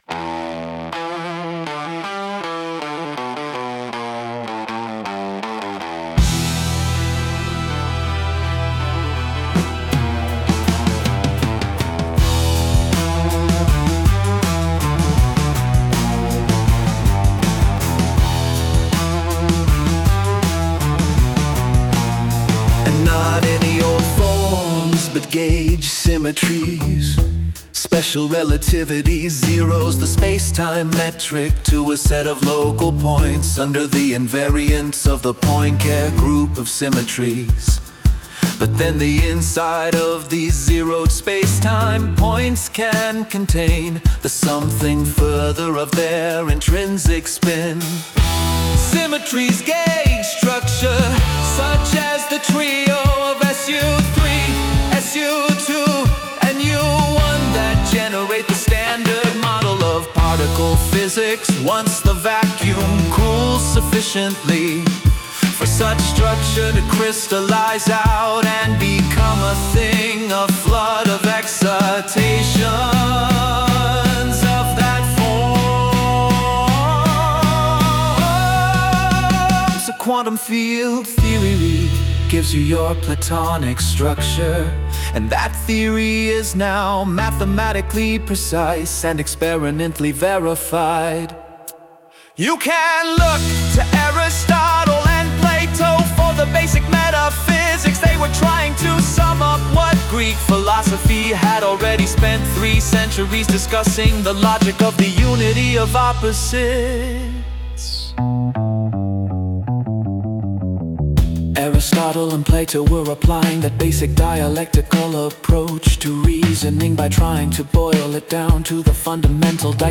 Is this psych rock?: